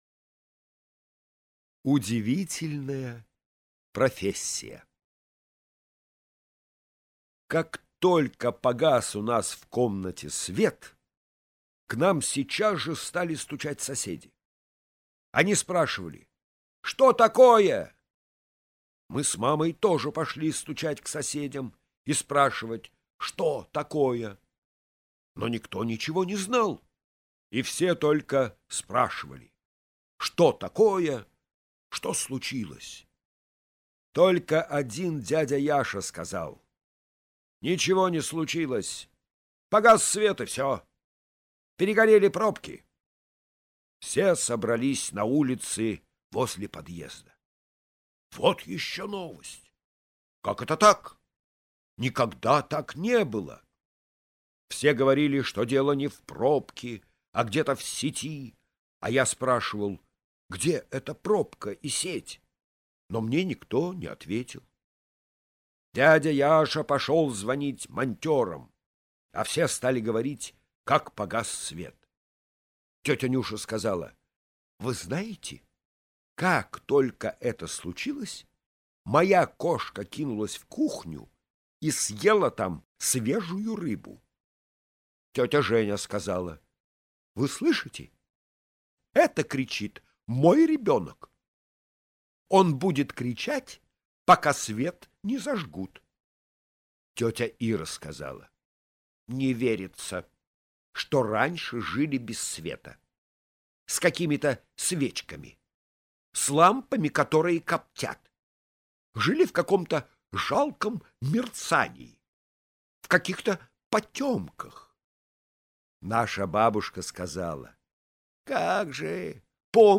Аудиорассказ «Удивительная профессия»